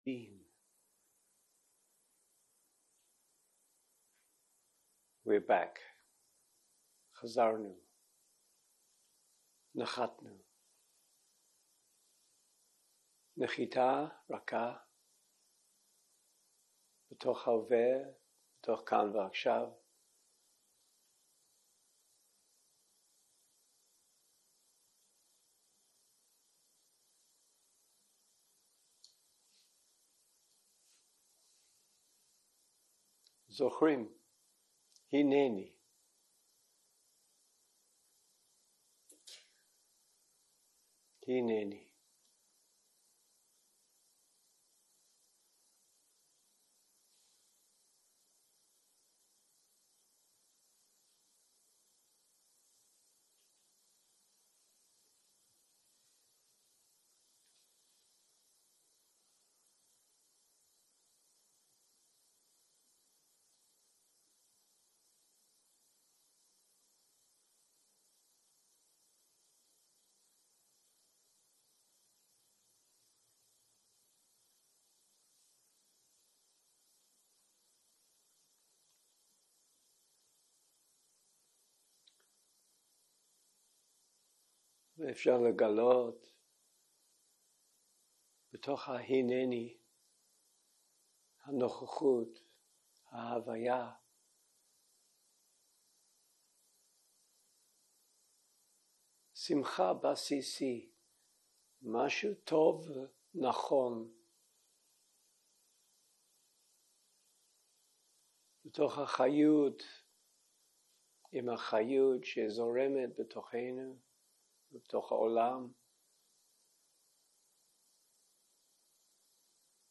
הקלטה 15 - יום 6 - צהרים - מדיטציה מונחית - מטא לחבר במצוקה
הקלטה 15 - יום 6 - צהרים - מדיטציה מונחית - מטא לחבר במצוקה 0:00 0:00 סוג ההקלטה: Dharma type: Guided meditation שפת ההקלטה: Dharma talk language: English